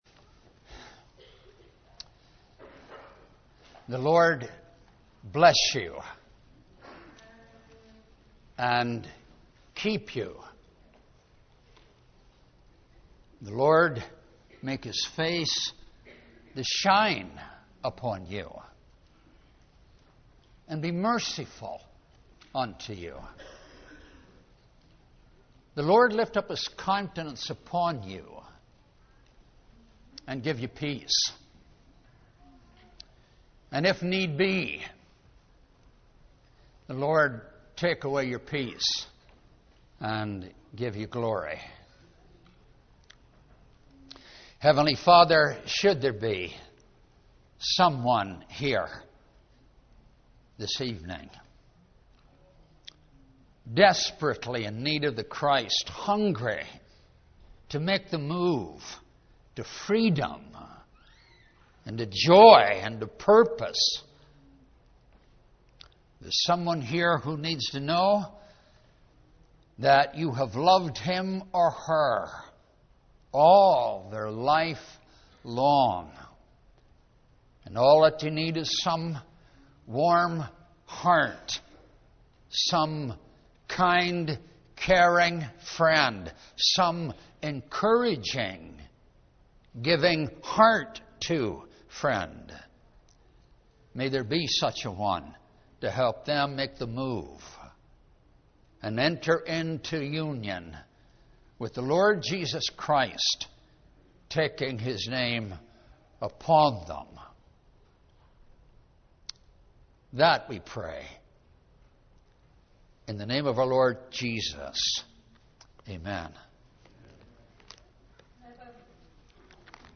Lectureship - 2011
Sermons